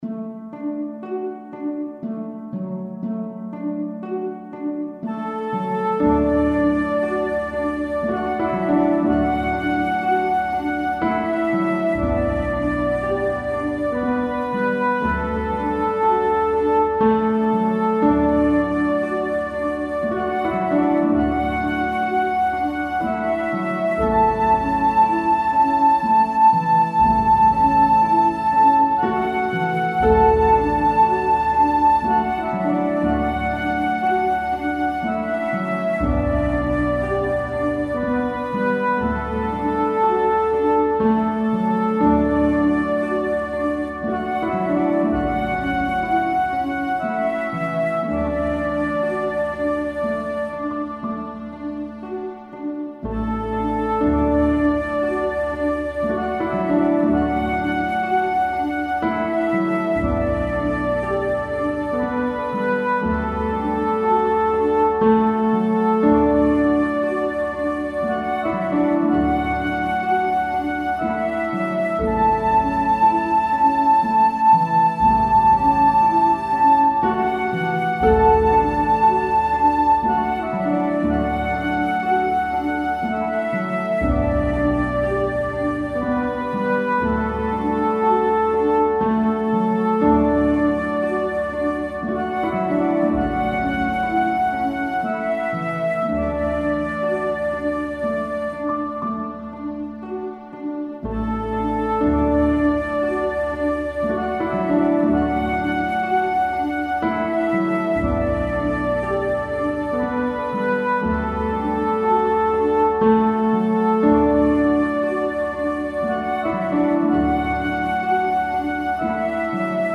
Hymne / Berceuse, États-Unis
Télécharger mp3 instrumental pour Karaoké
Christmas Karaoke
instrumental, 4:10 – 4/4 – 60 bpm